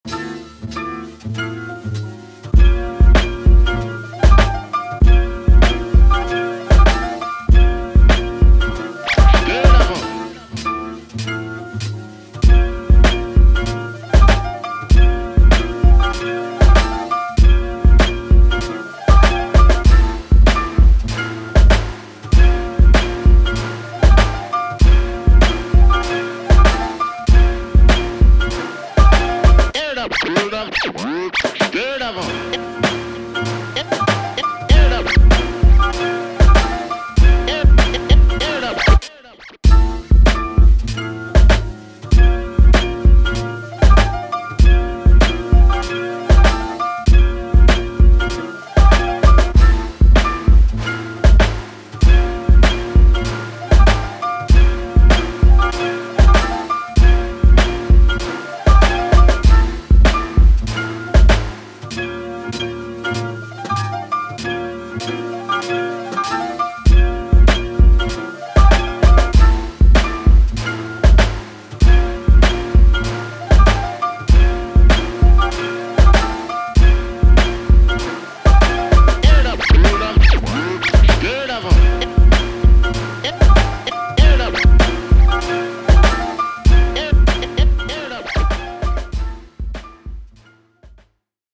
a summer rendition